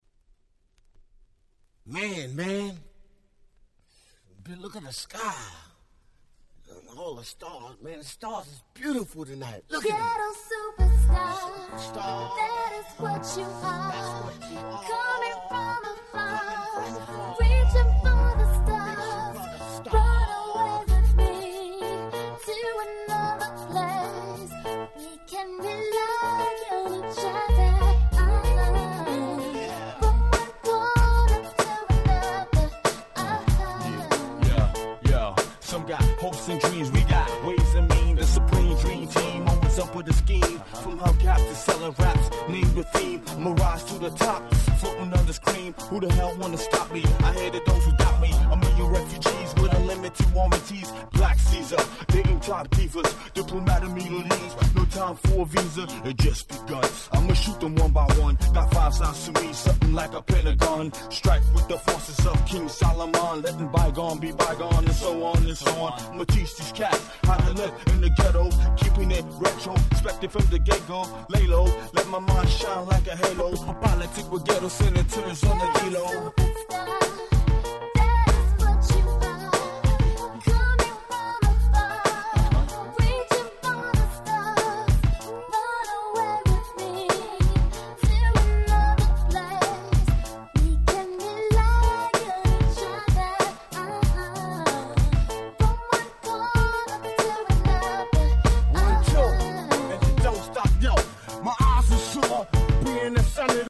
White Press Only Remix !!